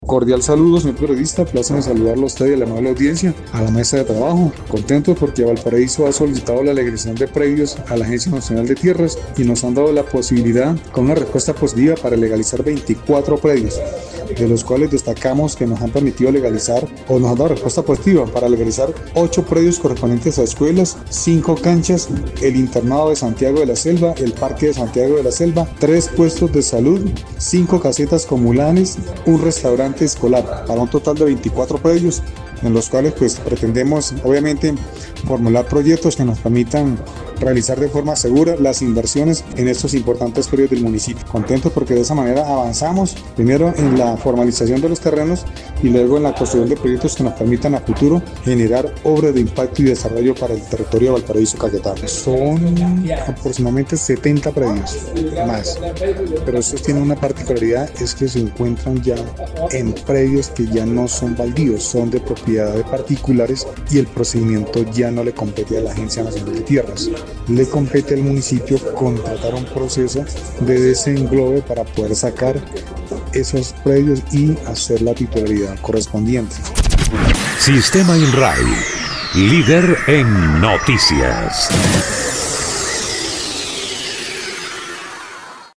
Así lo dio a conocer el alcalde del municipio de Valparaíso, Arbenz Pérez Quintero.